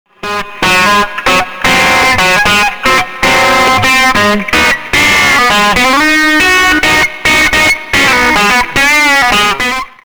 中域をコントロールするイコライザ・エフェクター。ギターやベースのキャラクターを積極的に調整可能。バッキングとリードサウンドの切り替えや、ギターソロ時のブースターとして活躍します。